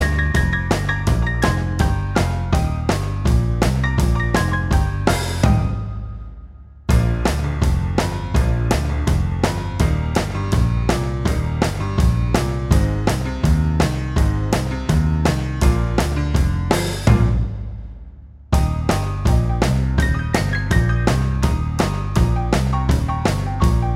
Minus Guitars Rock 'n' Roll 2:35 Buy £1.50